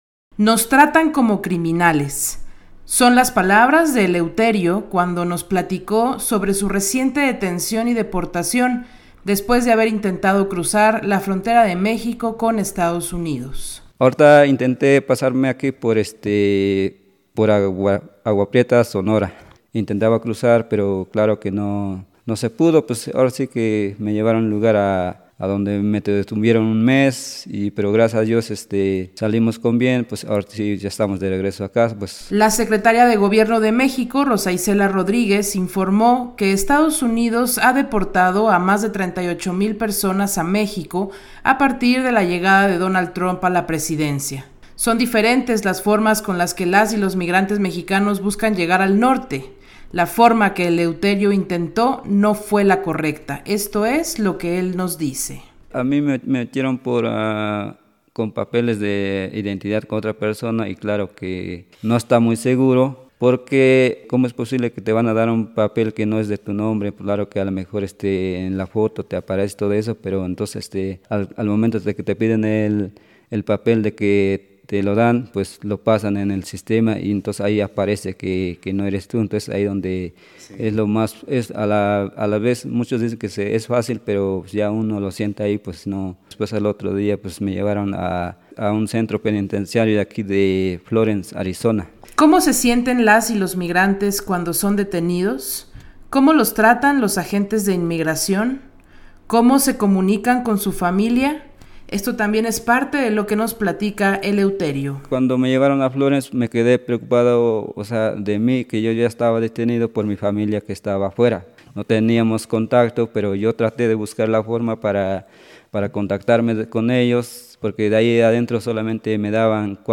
Ambos comparten su testimonio en entrevista para Radio Huayacocotla.